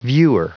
Prononciation du mot viewer en anglais (fichier audio)
Prononciation du mot : viewer